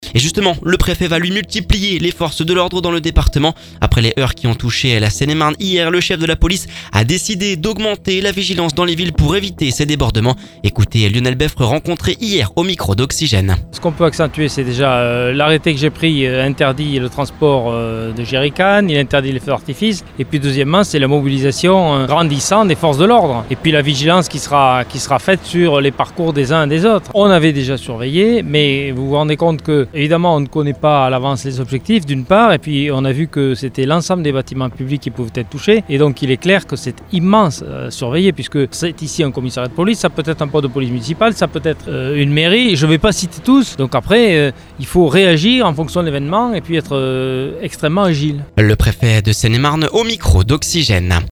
Après les heurts qui ont touché la Seine-et-Marne hier et mercredi dans la soirée, le chef de la police a décidé d’augmenter la vigilance dans les villes pour minimiser les débordements. Lionel Beffre rencontré hier au micro d’Oxygène…